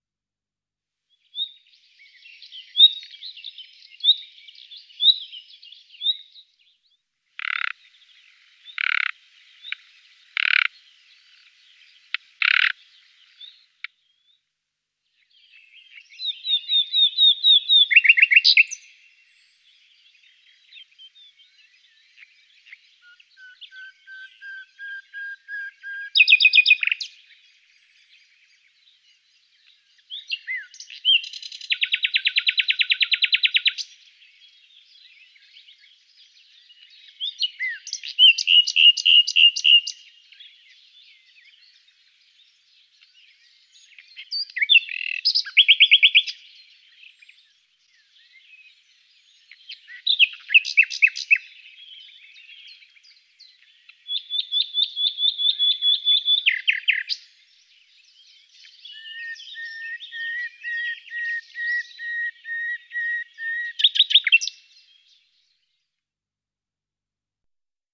Usignolo
Luscinia megarhynchos
Richiami ‘uiit’, un basso ‘krrr’, ‘tek tek’. Canto sonoro, lungo e melodioso, molto simile a quello dell’Usignolo maggiore, ma più veloce. A differenza dell’Usignolo maggiore non ha note che ricordano una tordela, ma presenta un caratteristico crescendo.
Usignolo.mp3